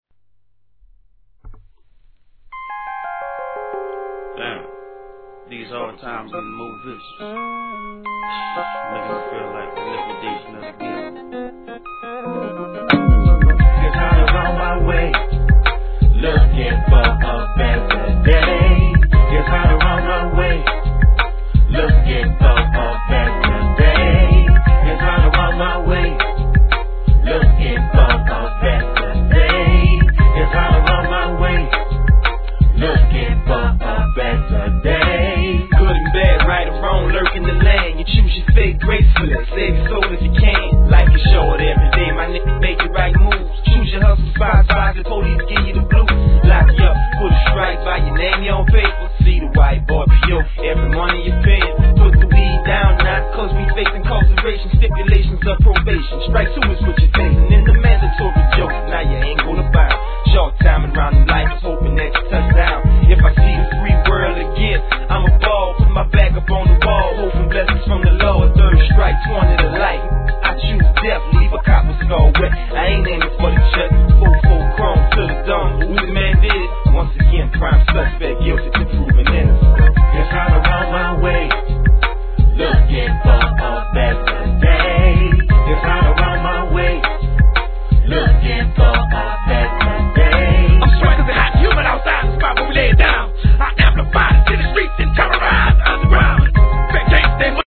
G-RAP/WEST COAST/SOUTH
メロ〜トラックにコーラスが絡み何ともムーディーなトラックに